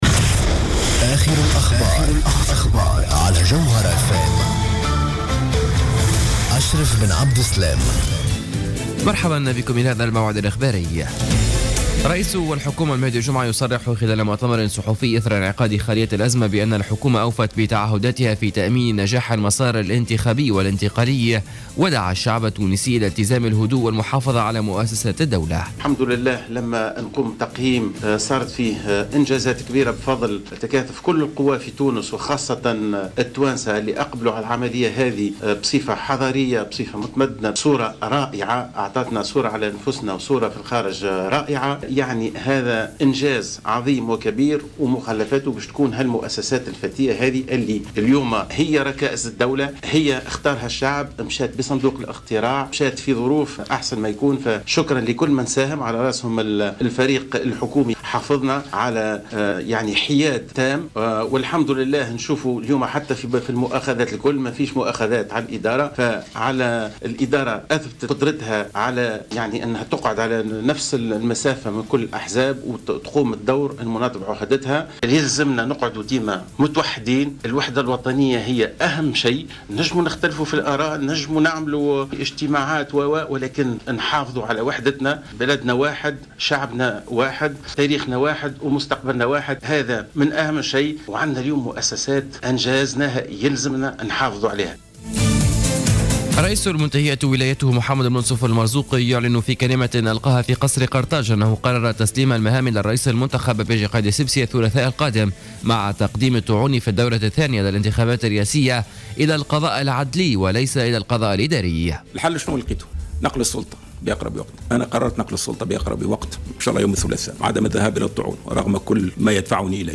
نشرة اخبار منتصف الليل ليوم الخميس 25-12-14